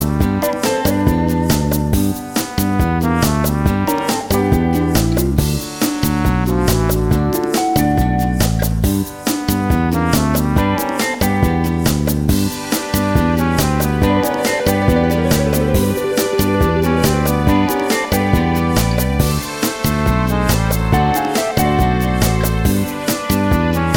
no Backing Vocals